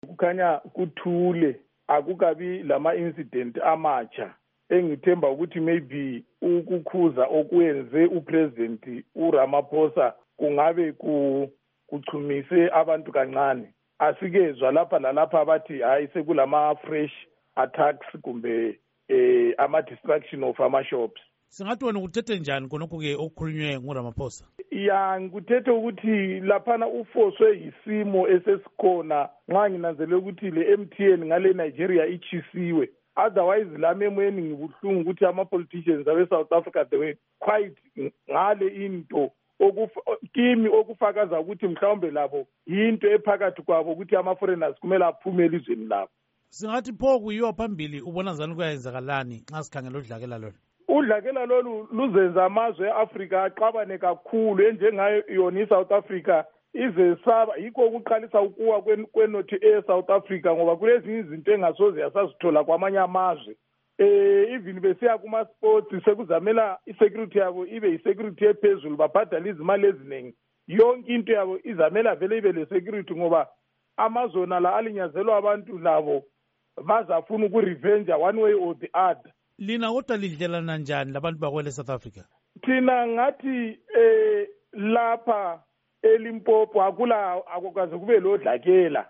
Ingxoxo Esiyenze LoMnu.